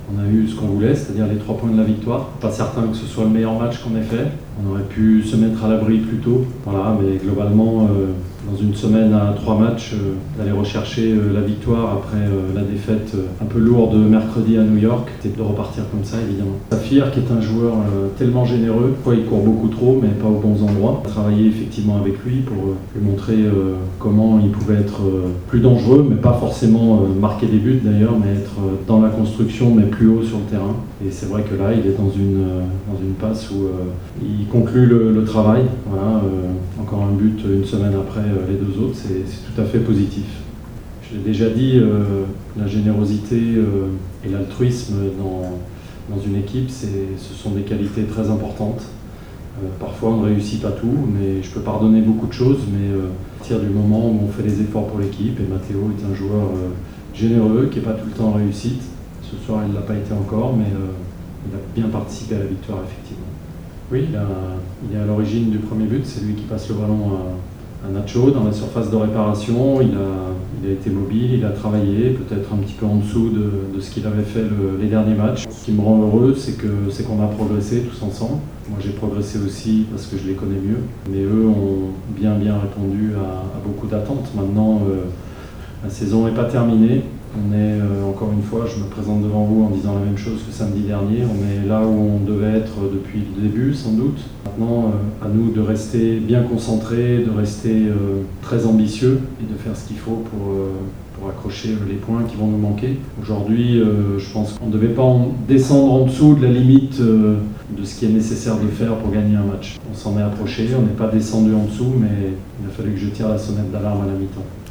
Le interviste post-partita: